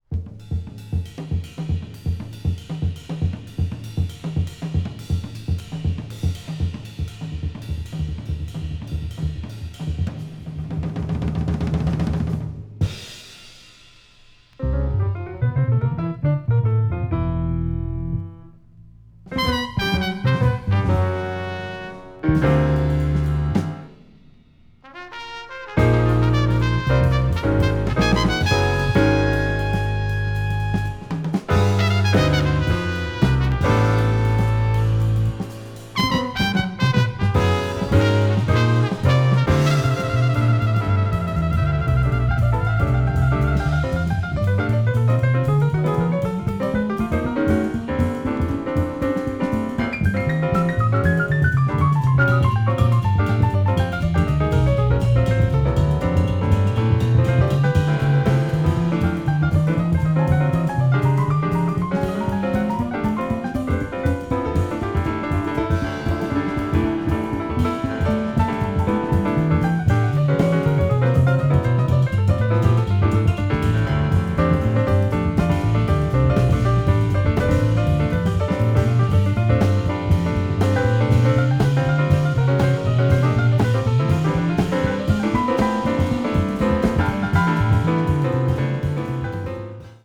contemporary jazz   post bop   spritual jazz